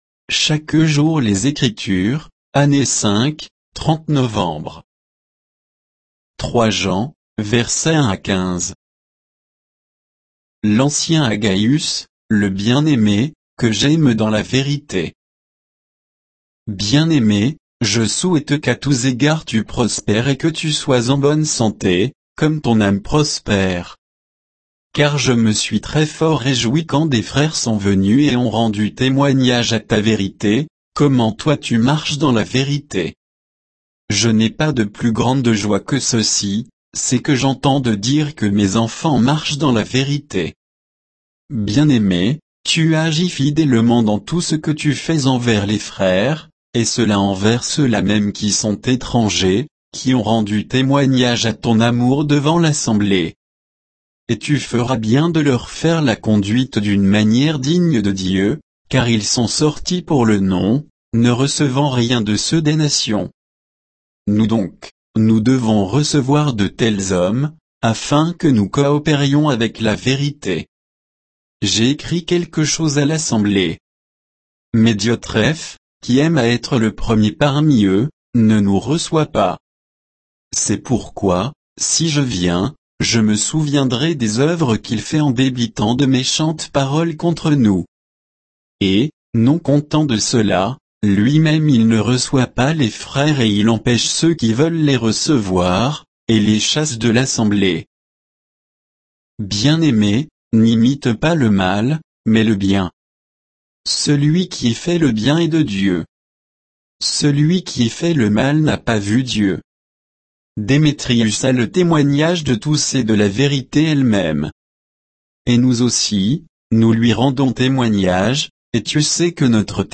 Méditation quoditienne de Chaque jour les Écritures sur 3 Jean 1 à 15